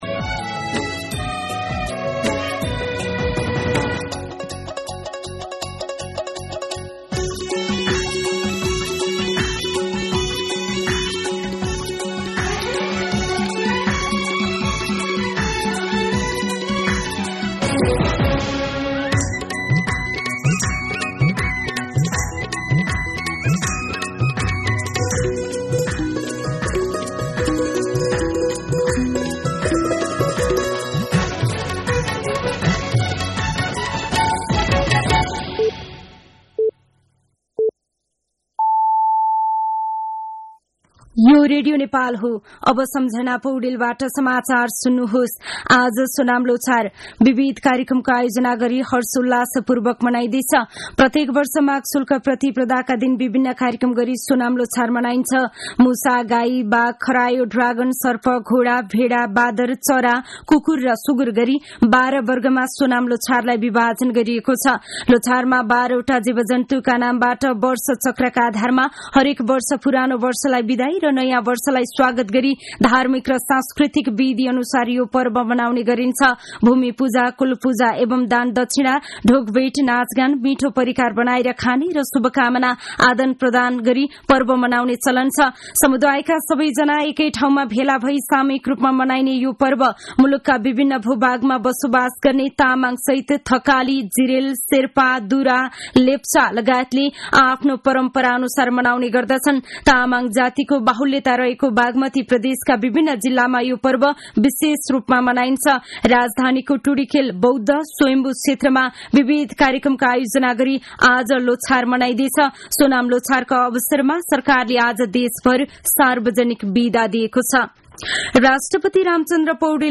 मध्यान्ह १२ बजेको नेपाली समाचार : १८ माघ , २०८१